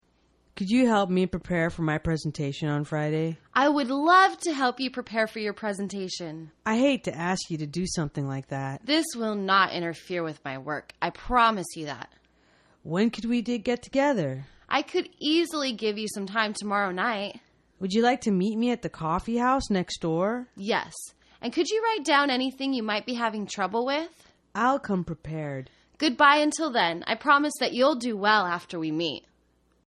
工作英语对话:Making Promises(2) 听力文件下载—在线英语听力室